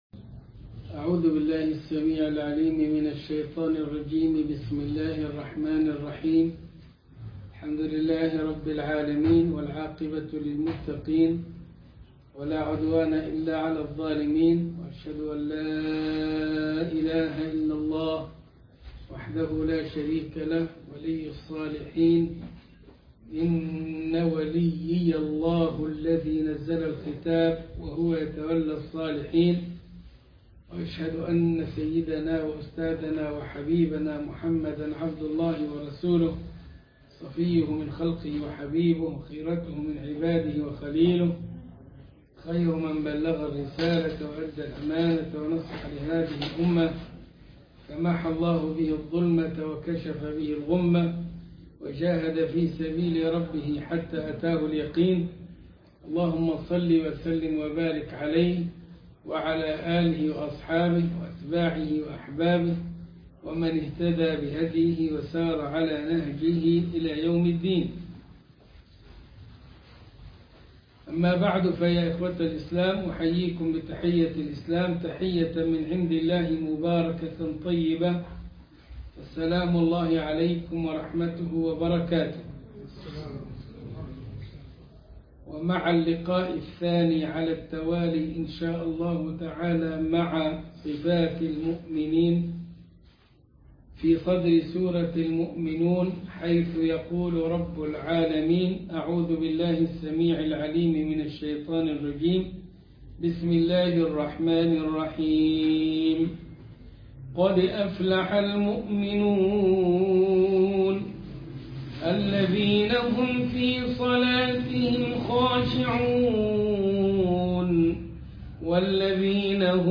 عنوان المادة المحاضرة الثانية - صفات المؤمنين في صدر سورة المؤمنون تاريخ التحميل الأحد 20 ديسمبر 2020 مـ حجم المادة 30.75 ميجا بايت عدد الزيارات 229 زيارة عدد مرات الحفظ 100 مرة إستماع المادة حفظ المادة اضف تعليقك أرسل لصديق